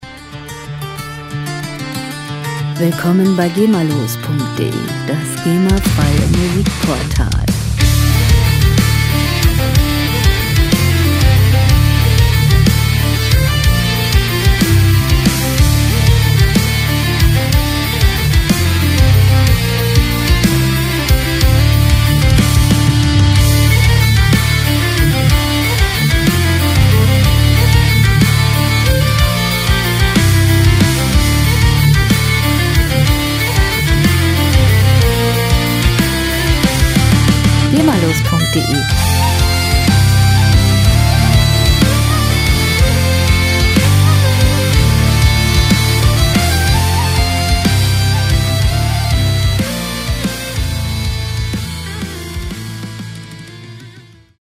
Rockmusik - Harte Männer
Musikstil: Viking Metal
Tempo: 93 bpm
Tonart: D-Moll
Charakter: episch, triumphal